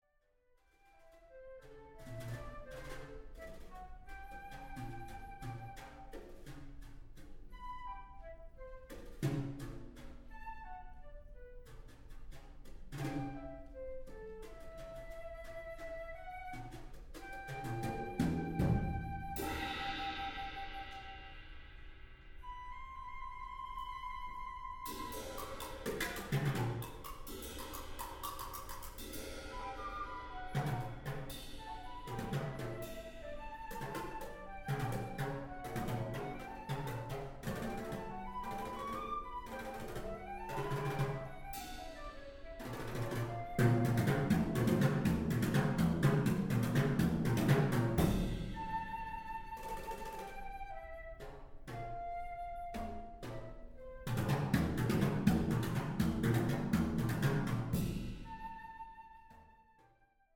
Flute and Percussion
Scored for a duo of flute and multi-percussion.